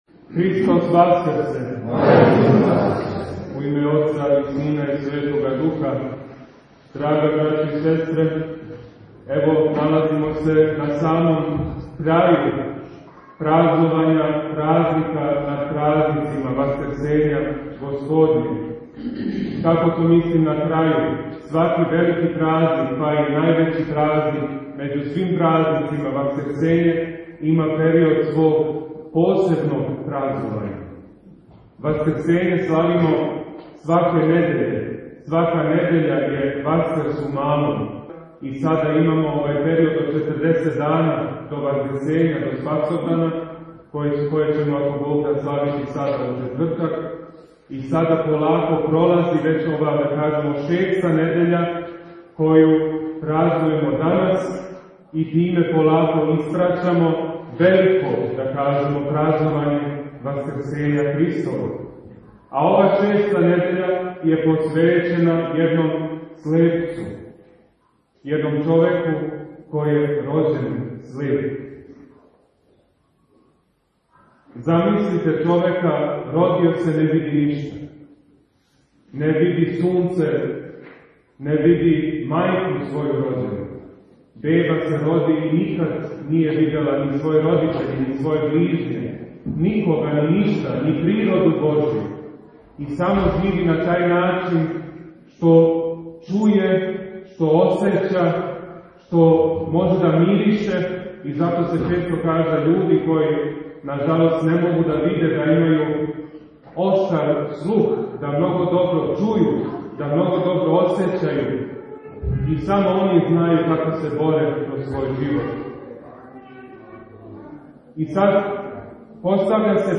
Беседа у Недељу о слепом